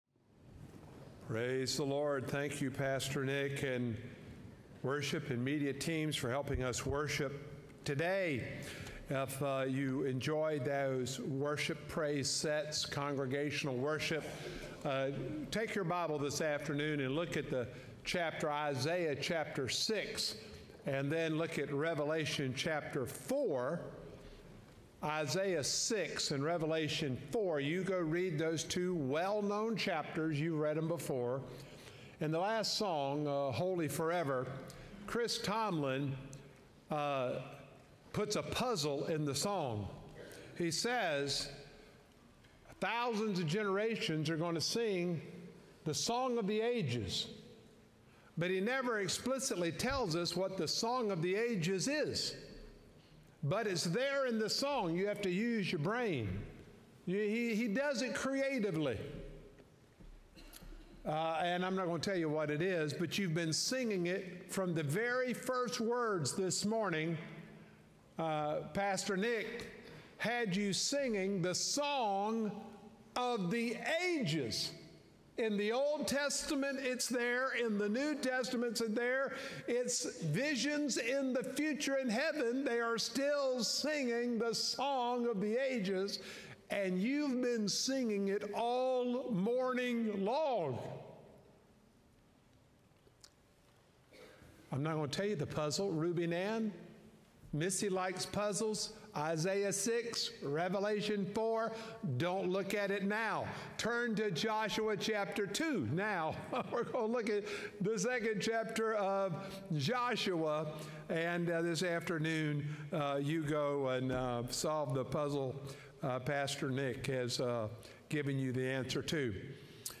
October-5-2025-Sermon-Audio.m4a